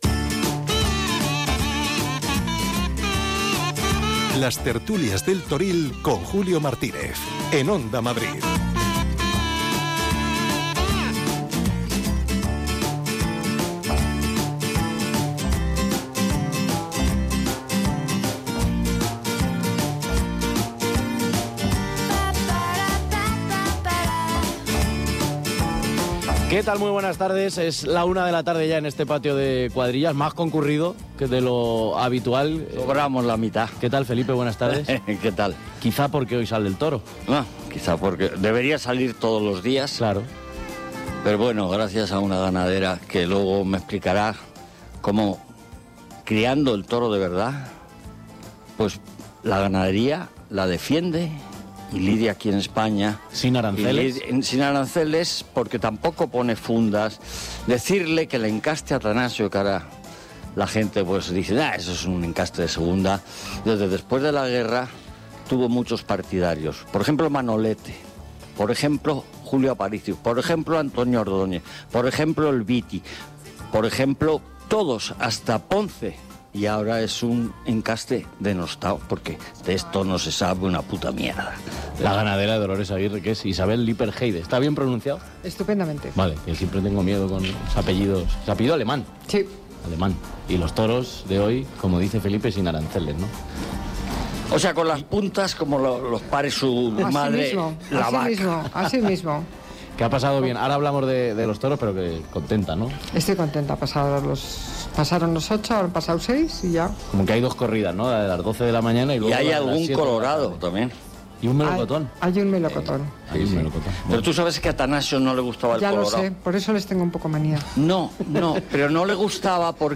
entrevistará a toreros, ganaderos y expertos del sector cada día desde Las Ventas.